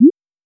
recharge_capsule_1.wav